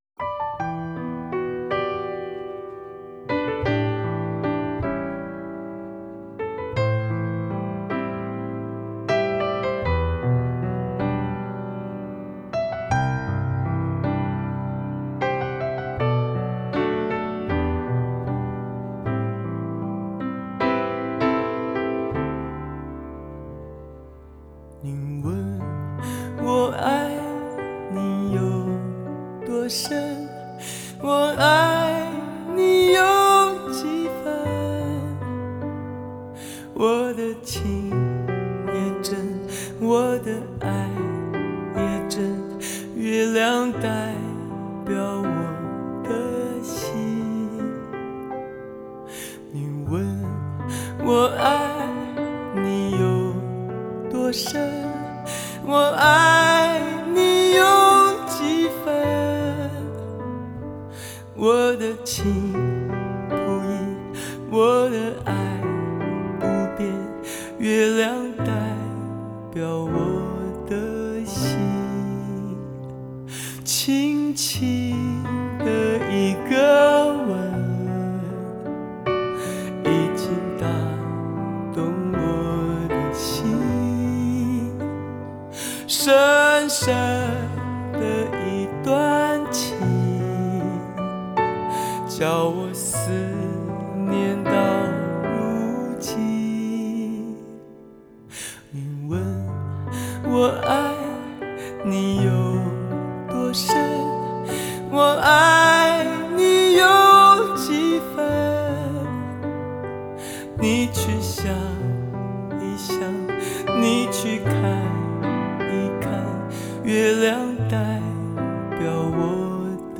类别: 国风